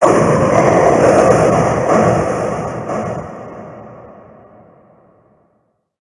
Fichier:Cri 0099 Gigamax HOME.ogg — Poképédia
Cri de Krabboss Gigamax dans Pokémon HOME.